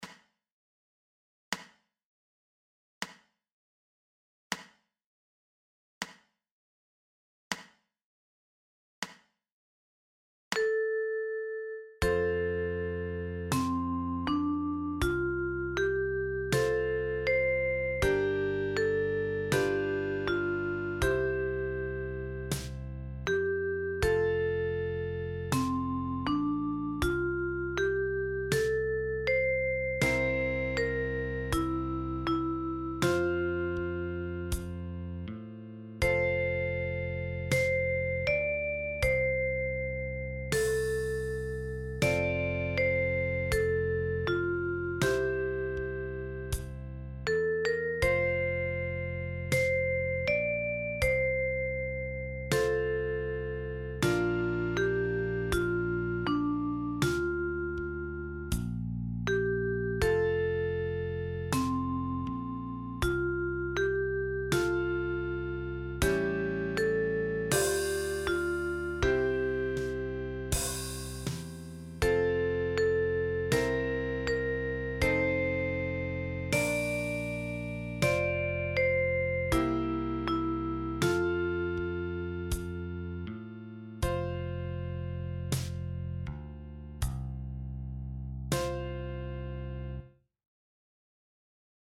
für die Okarina mit 6 Löchern